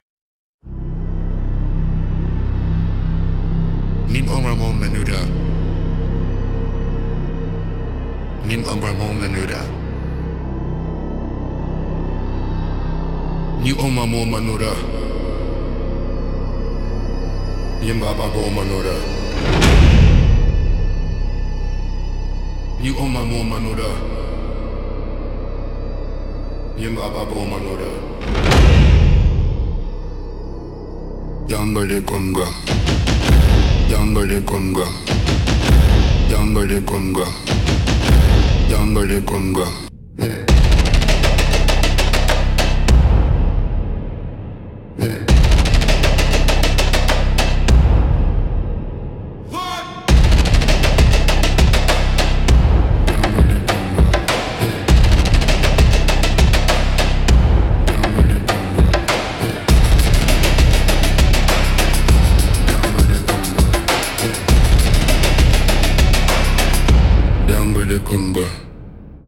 Instrumental - Ember Veil -1.09